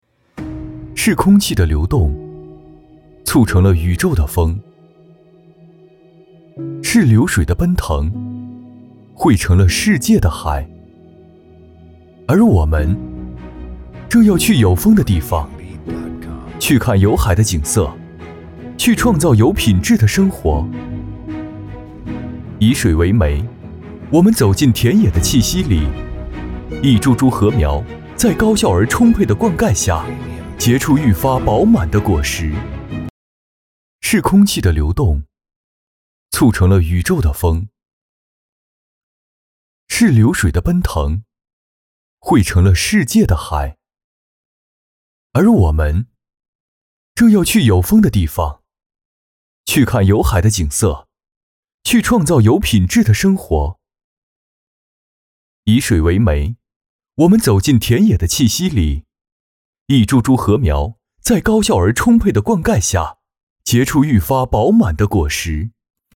男28号
新界品牌概念（宣传片）